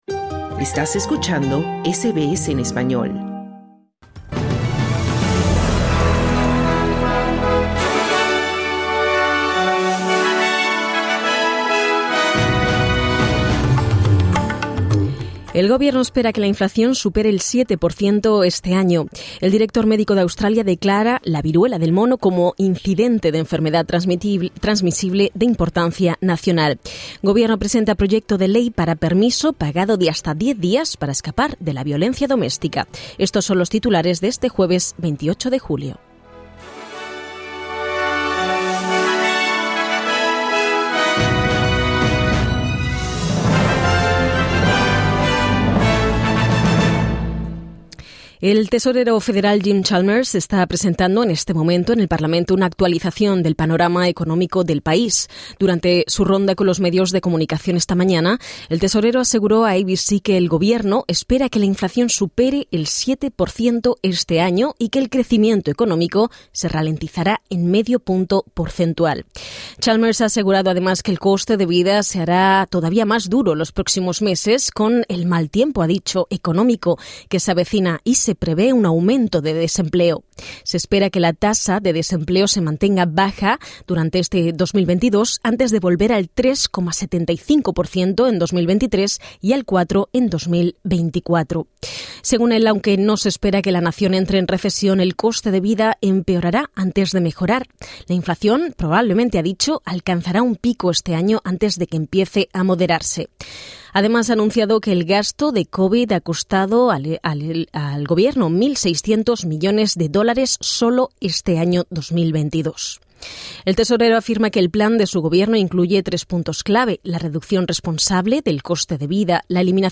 El gobierno australiano espera que la inflación supere el siete por ciento este año y ha dicho que se prevé una ralentización del crecimiento económico de medio punto porcentual. Escucha esta y otras noticias importantes del día.